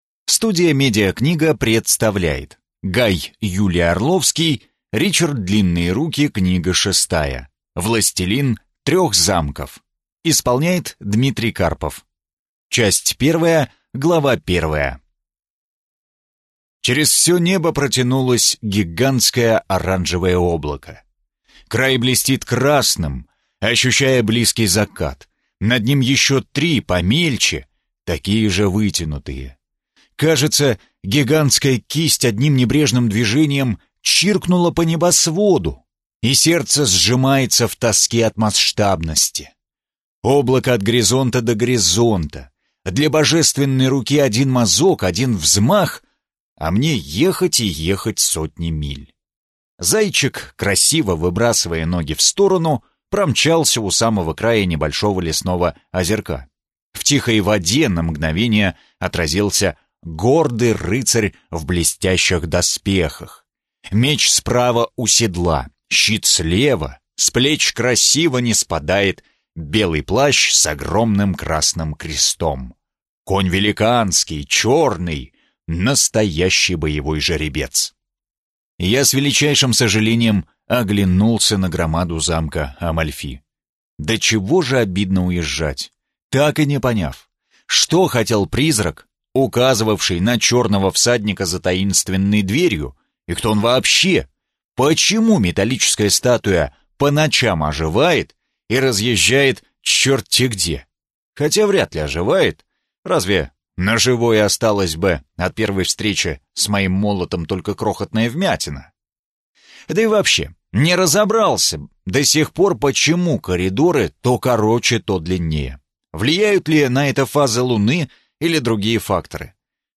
Аудиокнига Властелин трех замков | Библиотека аудиокниг